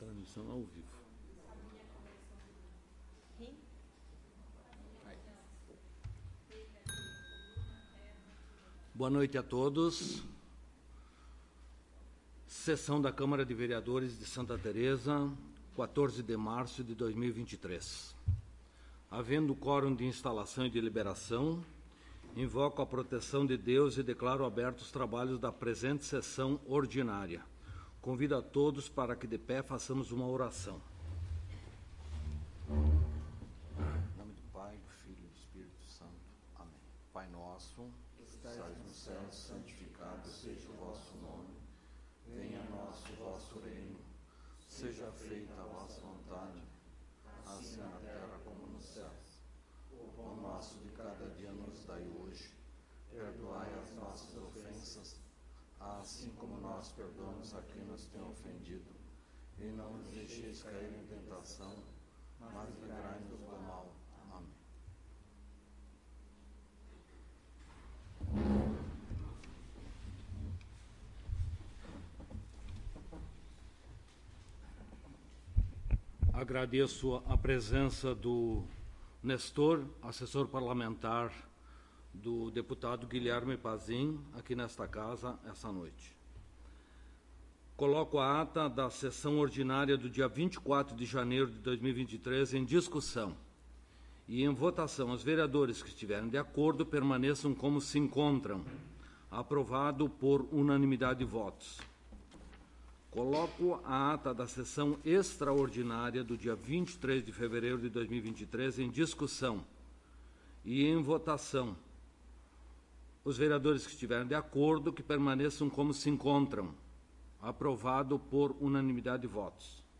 03° Sessão Ordinária de 2023
Áudio da Sessão